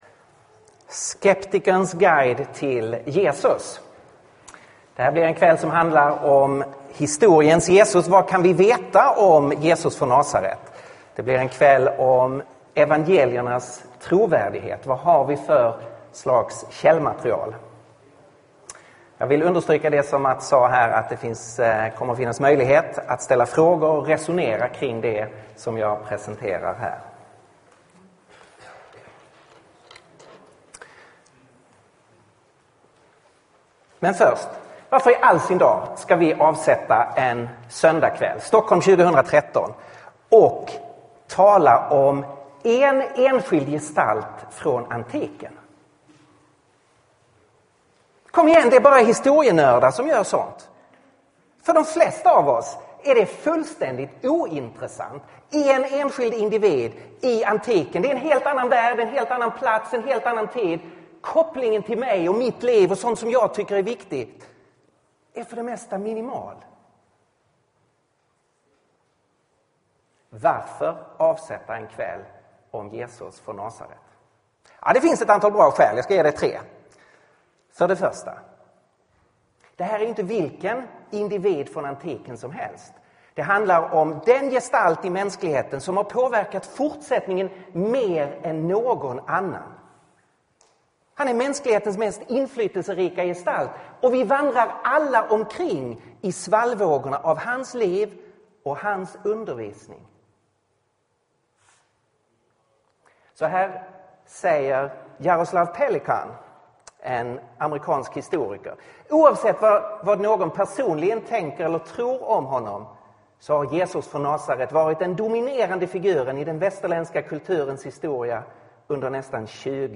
Predikan är inspelad 3 mars 2013.